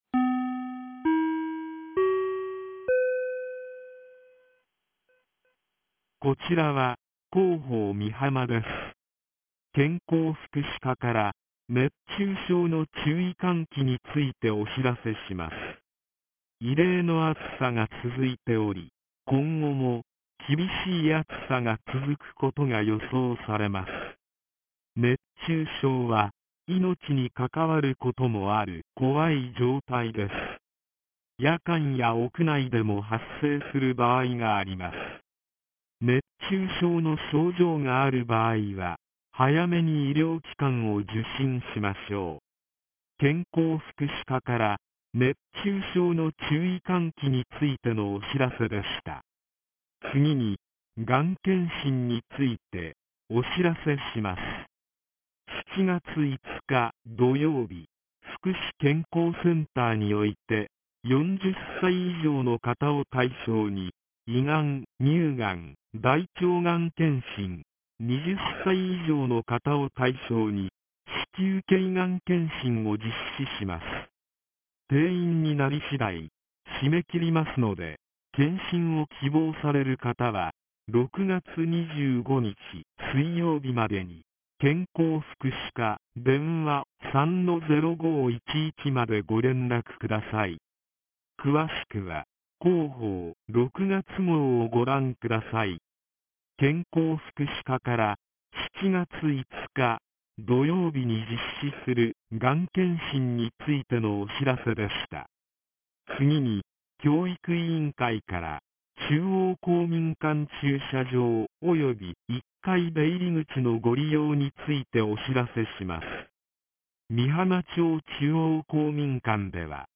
■防災行政無線情報■